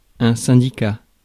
Ääntäminen
UK
IPA : /ˈtɹeɪd ˈjuːn.jən/